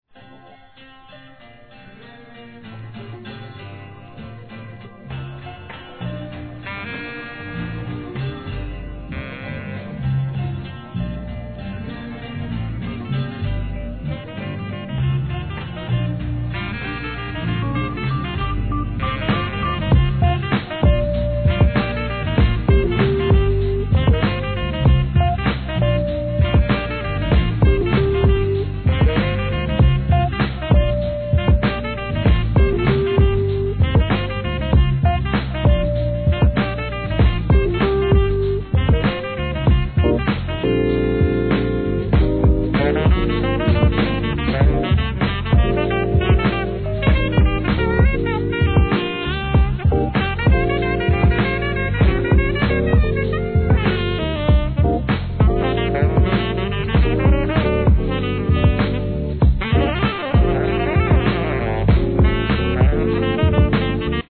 HIP HOP/R&B
90'Sヒップホップ好きは涙の名曲がジャジー＆メロウに甦る！